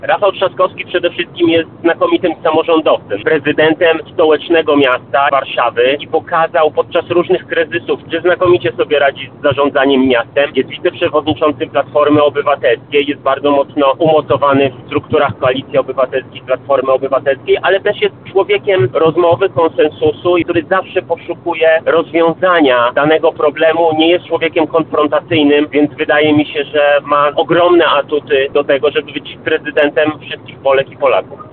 – To bardzo silny mandat, ogromne poparcie od wszystkich partii wchodzących w skład KO – mówi poseł Platformy Obywatelskiej Michał Krawczyk.